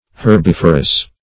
Meaning of herbiferous. herbiferous synonyms, pronunciation, spelling and more from Free Dictionary.
herbiferous.mp3